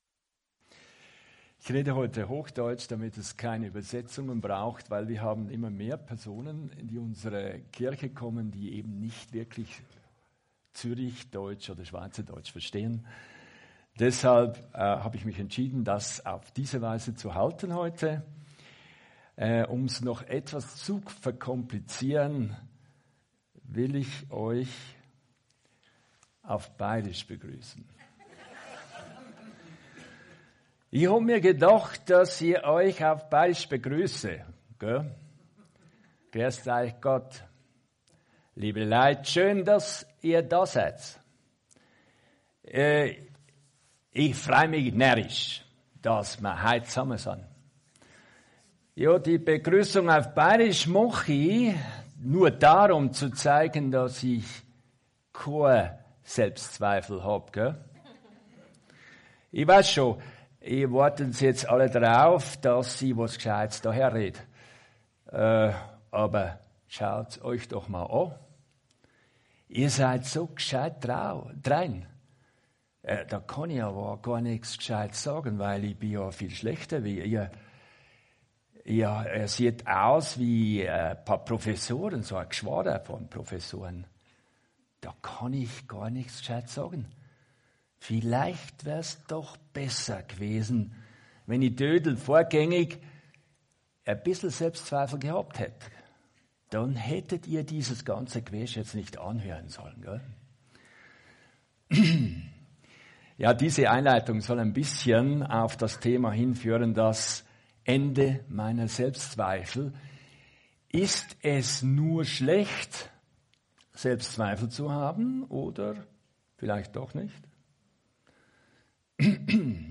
Weitere Predigten